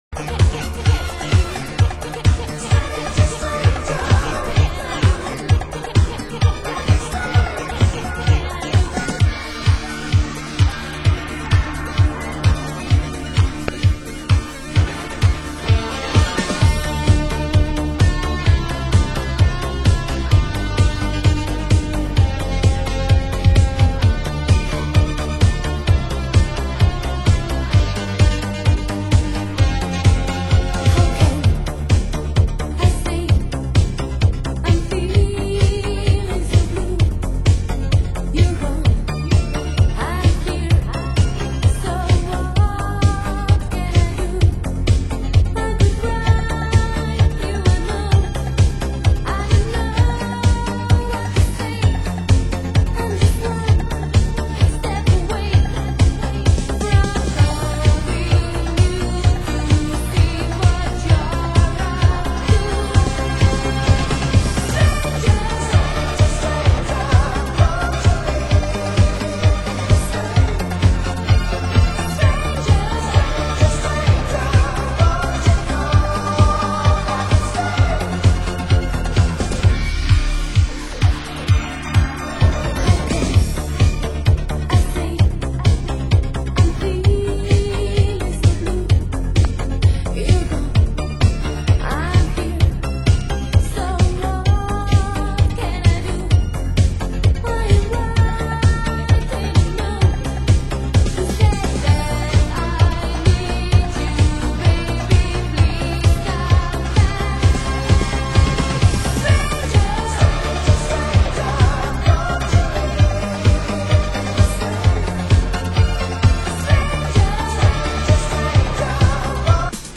Genre: Italo Disco